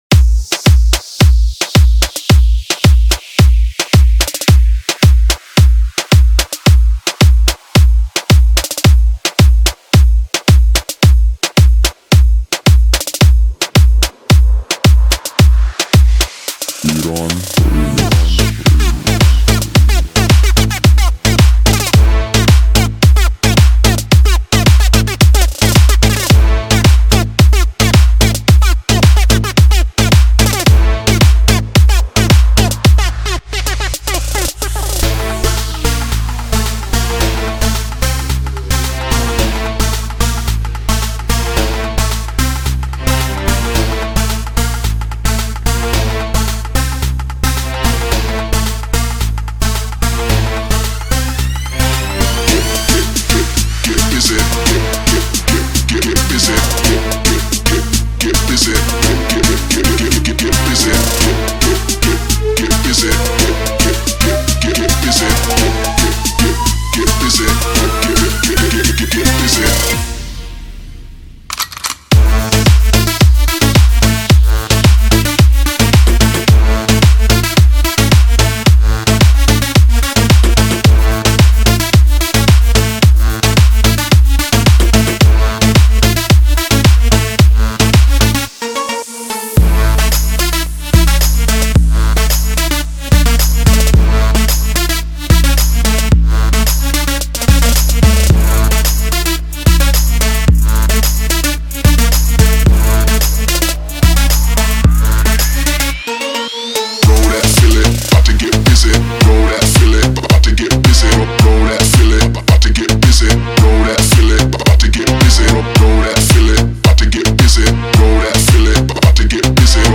Download the remix for the night party